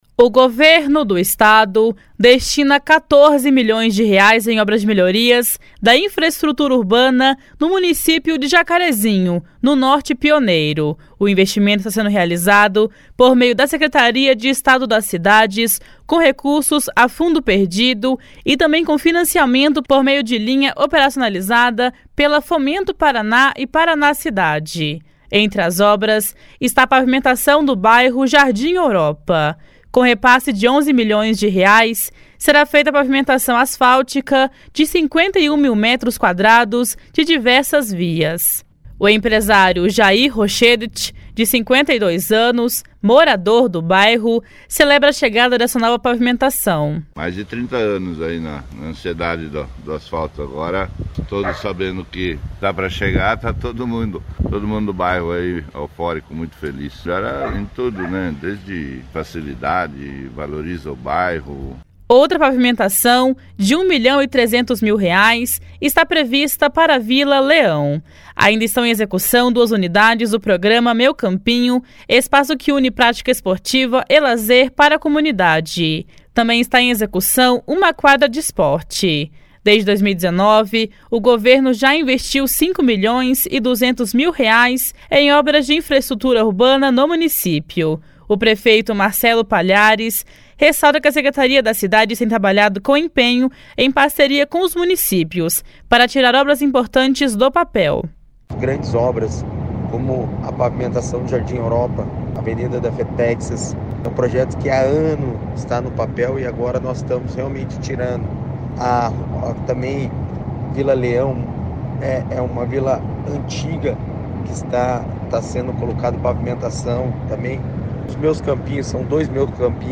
O prefeito Marcelo Palhares ressalta que a Secretaria das Cidades tem trabalhado com empenho em parceria com os municípios para tirar obras importantes do papel.
Para o secretário das Cidades, Eduardo Pimentel, as obras em Jacarezinho seguem uma orientação do governador Ratinho Junior.